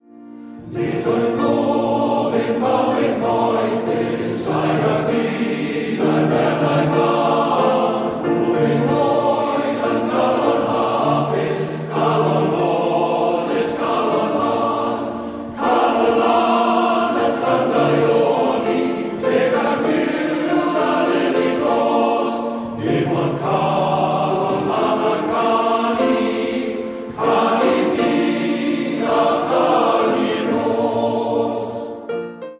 OGMORE VALLEY MALE VOICE CHOIR
Think of how the sound of a traditional Welsh Male Voice Choir will set everyone up for a great day of joy and celebrations.